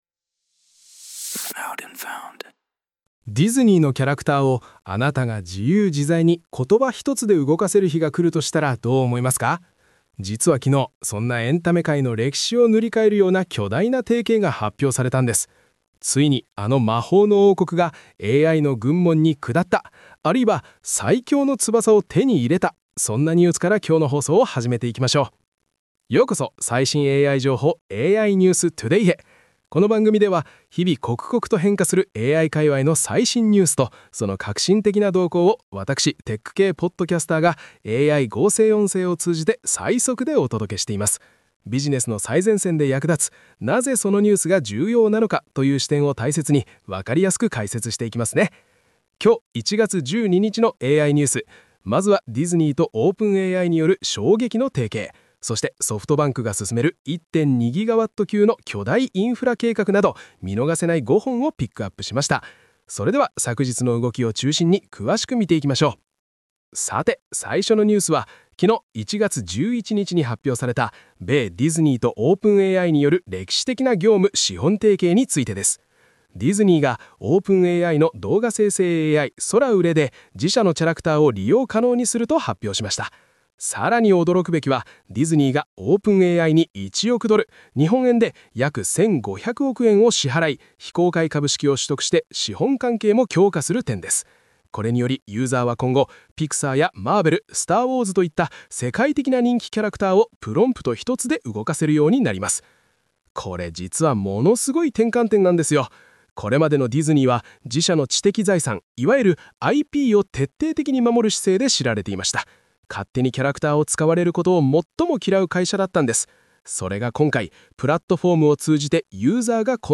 🤖 AI合成音声で最速ニュースをお届け